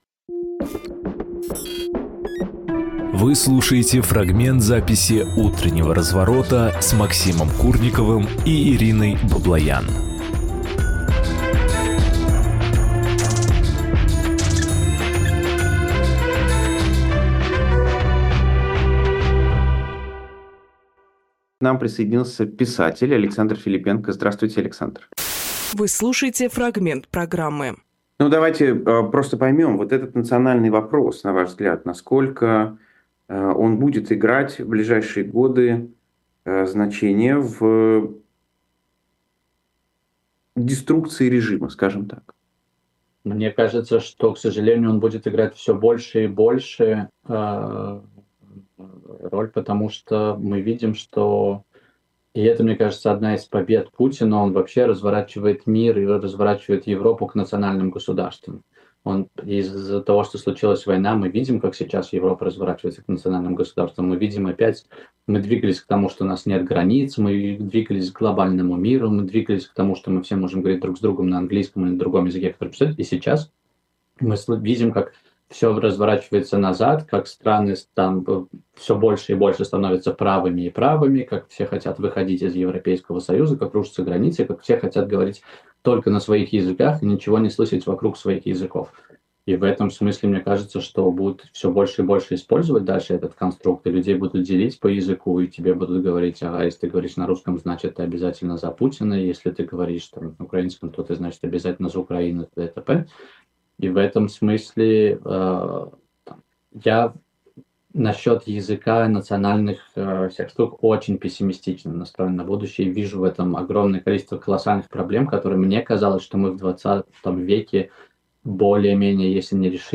Фрагмент эфира от 28.01.24